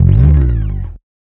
סמפלים של גיטרה באס עולה ויורד